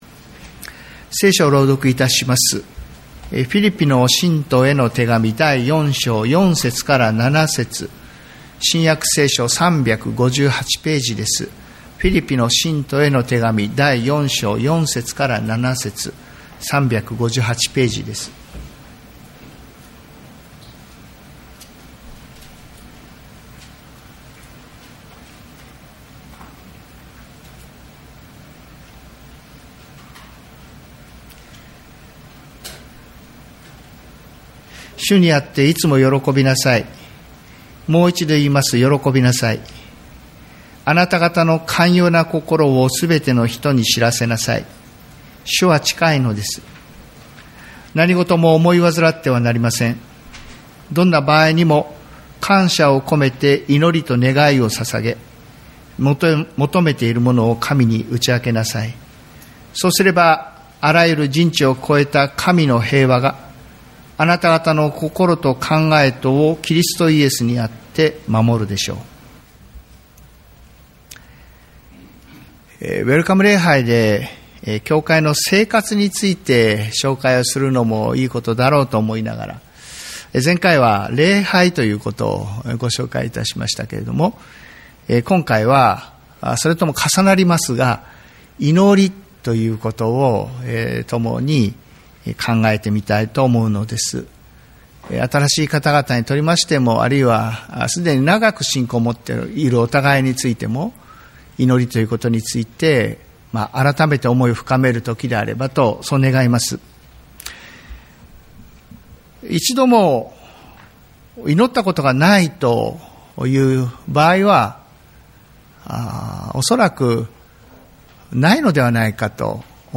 日本ホーリネス教団 八王子キリスト教会 礼拝説教